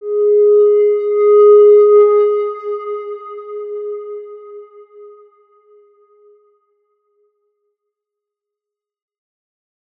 X_Windwistle-G#3-mf.wav